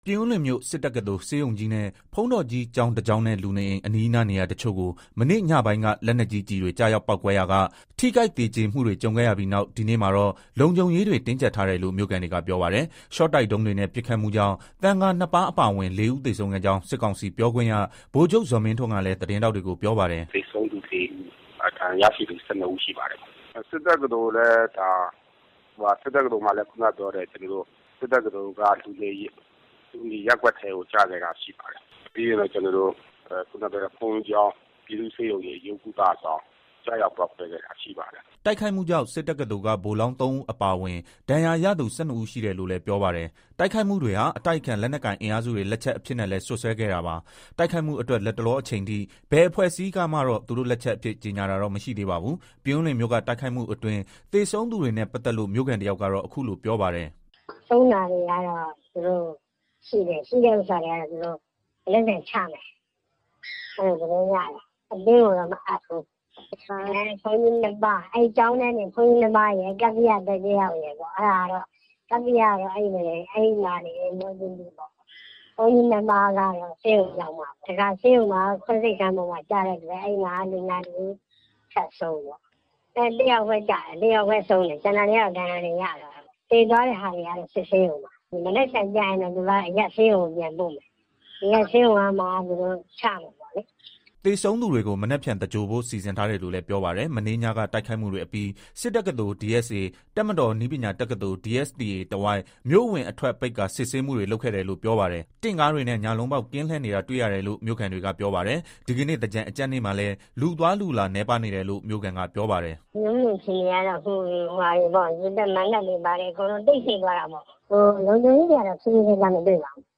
ပြင်ဦးလွင်မြို့က တိုက်ခိုက်မှုအတွင်း သေဆုံးသူတွေနဲ့ ပတ်သက်လို့ မြို့ခံတယောက်ကတော့ အခုလို ပြောပါတယ်။
တိုက်ခိုက်မှု ကြုံခဲ့ရပြီး နောက်တရက်ဖြစ်တဲ့ ဒီကနေ့ မန္တလေးမြို့ အခြေအနေနဲ့ ပတ်သက်လို့ မြို့ခံတယောက်က အခုလိုပြောပါတယ်။